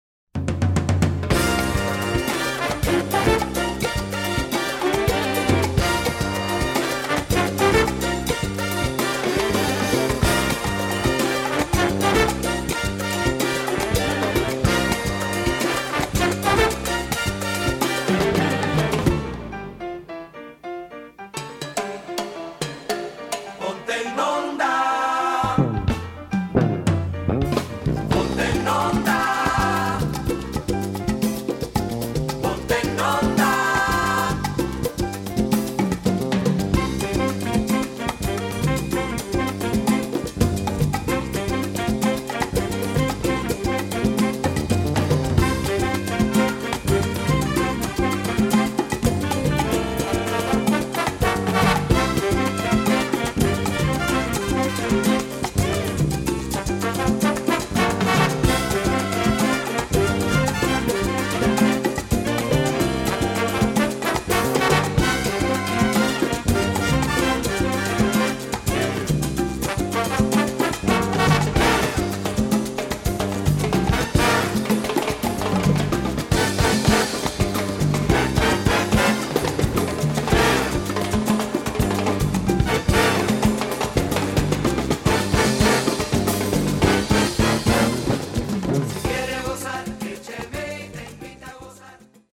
Category: big band
Style: son montuno
Solos: open
Instrumentation: big band (4-4-5, guitar, rhythm (4)